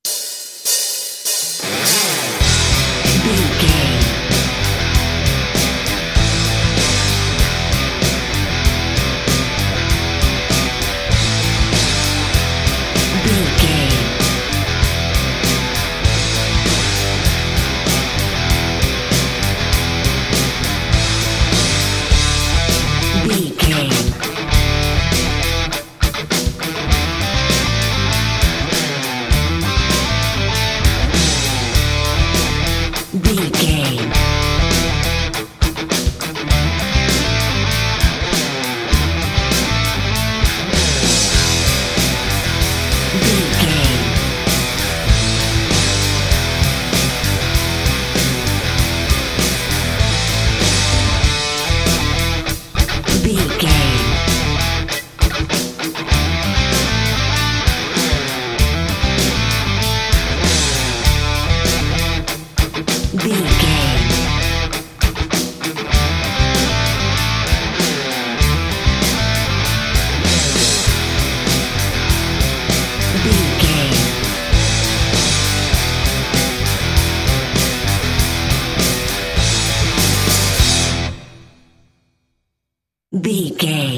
Aeolian/Minor
drums
electric guitar
pop rock
hard rock
lead guitar
bass
aggressive
energetic
intense
powerful
nu metal
alternative metal